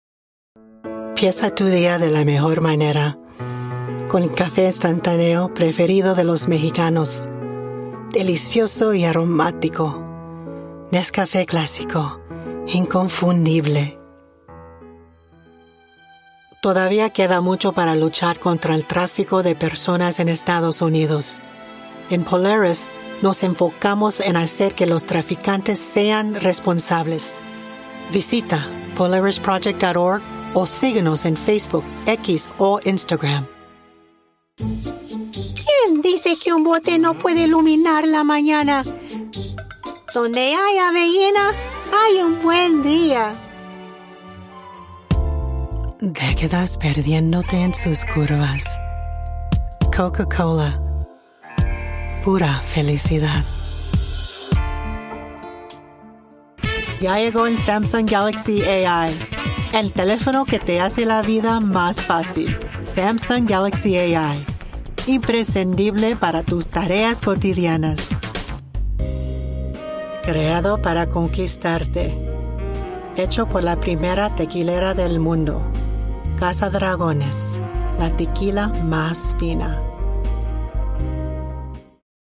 Professionally trained voice with boss lady, mom and nurturing vibes but versatile in character voices.
Commercial Demo with Character Sample
English - USA and Canada
I have a technology back ground and professional home studio with Source Connect connectivity.